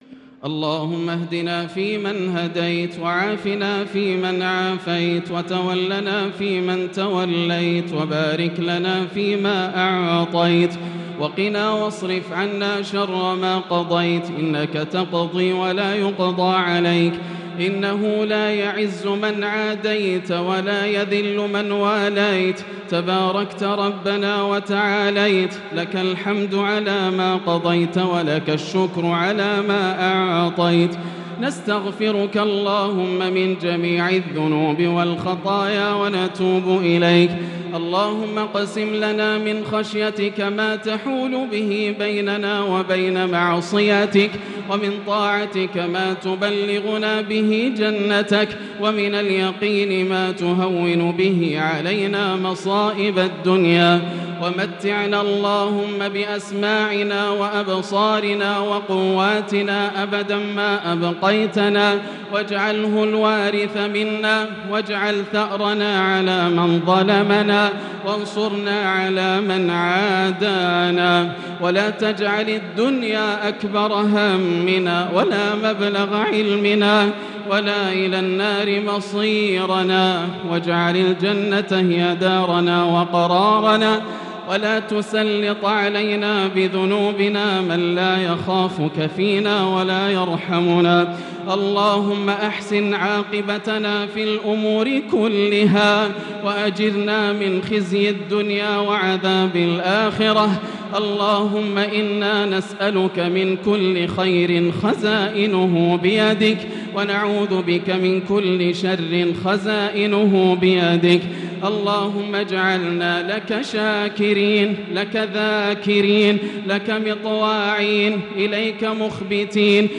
دعاء القنوت ليلة 14 رمضان 1443هـ | Dua for the night of 14 Ramadan 1443H > تراويح الحرم المكي عام 1443 🕋 > التراويح - تلاوات الحرمين